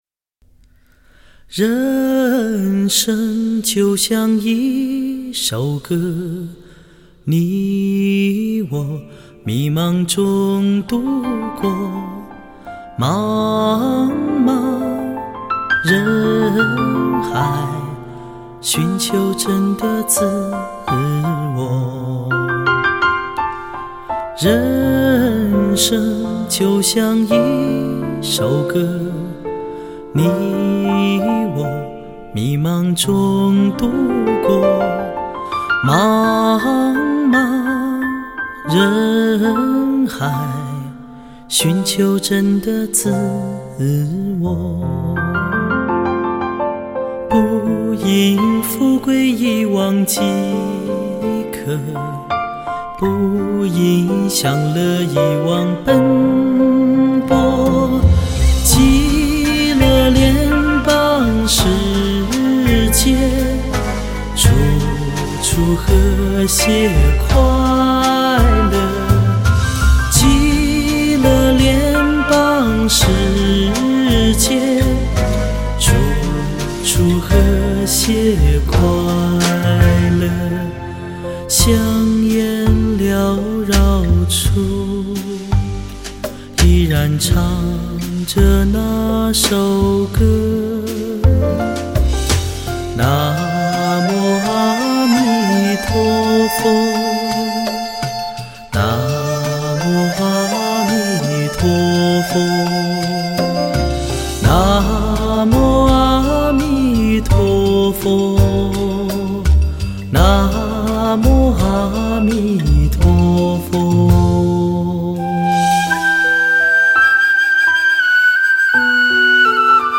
佛乐